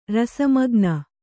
♪ rasa magna